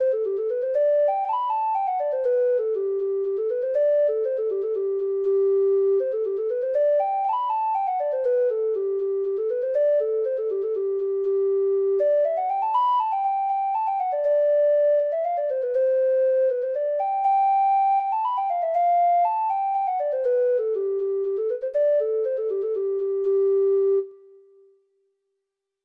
Traditional Trad. The Black Slender Boy (Irish Folk Song) (Ireland) Treble Clef Instrument version
Irish